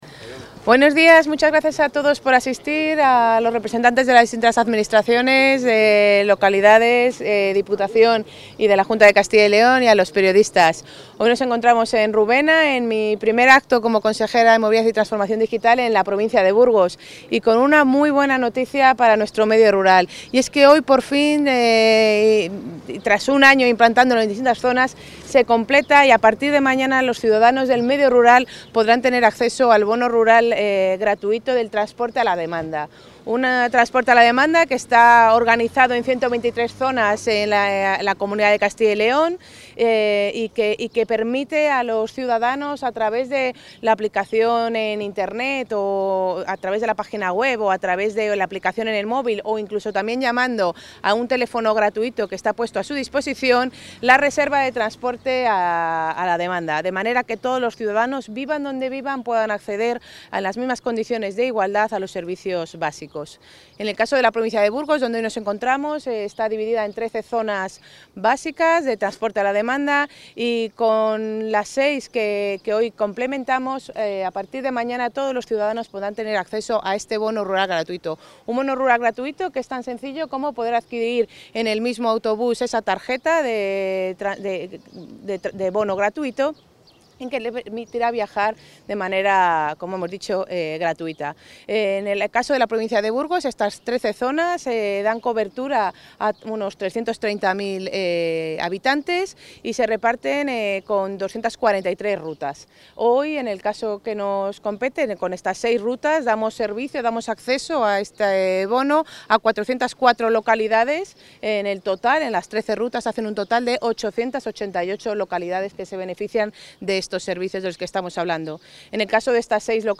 La consejera de Movilidad y Transformación Digital, María González Corral, ha presentado hoy en Rubena (Burgos) la...
Intervención de la consejera.